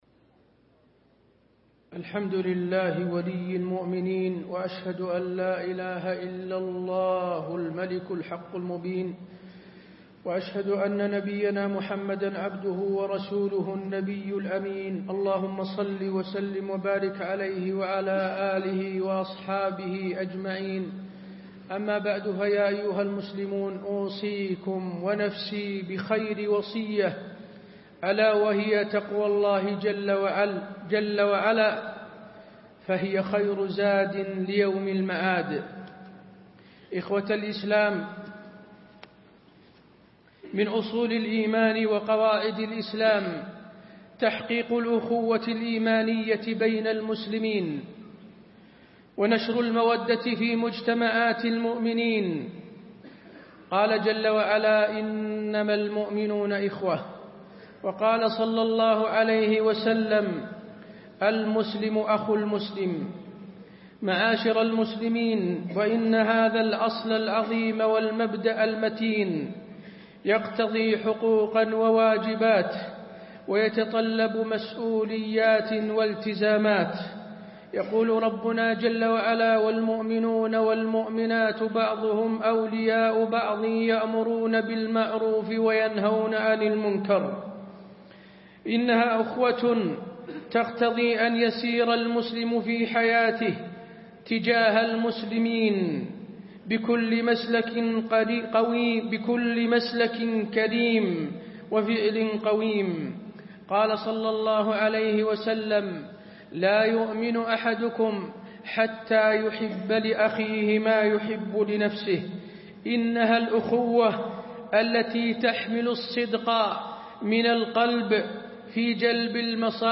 تاريخ النشر ٢٨ ذو القعدة ١٤٣٤ هـ المكان: المسجد النبوي الشيخ: فضيلة الشيخ د. حسين بن عبدالعزيز آل الشيخ فضيلة الشيخ د. حسين بن عبدالعزيز آل الشيخ الأخوة بين المسلمين